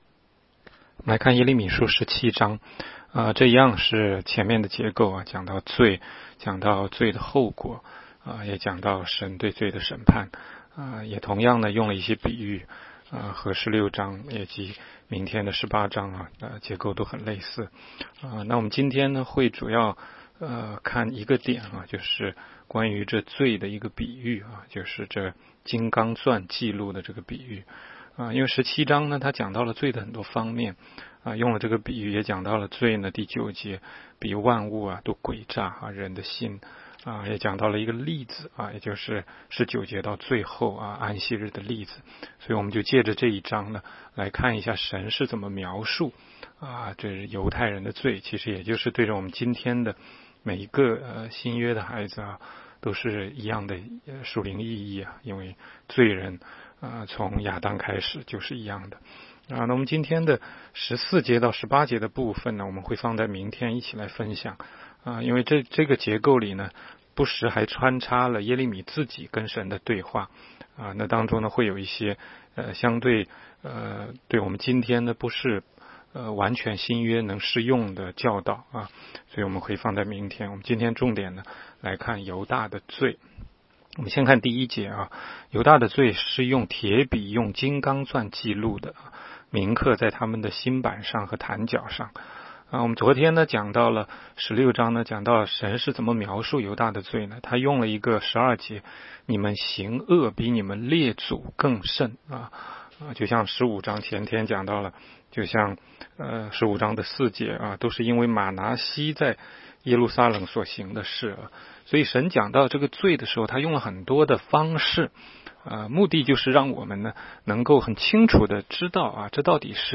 16街讲道录音 - 每日读经 -《耶利米书》17章